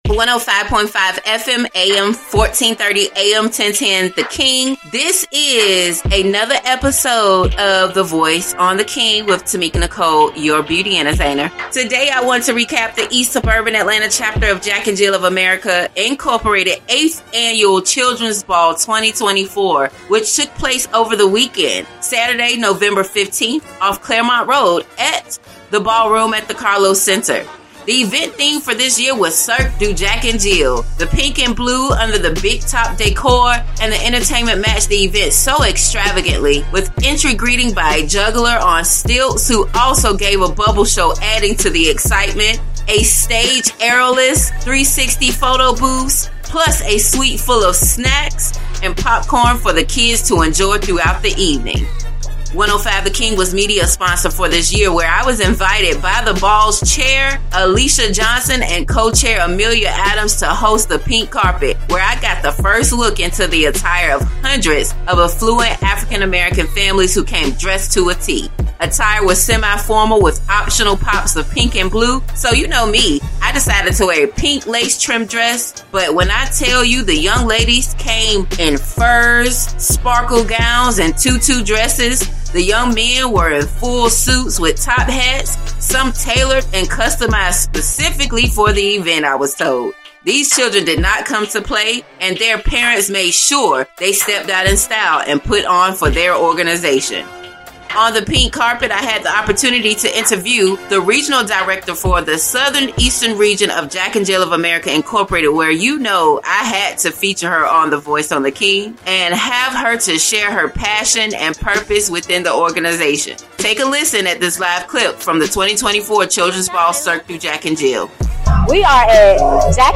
Heard on 105.5 FM/AM 1430 & AM 1010 The King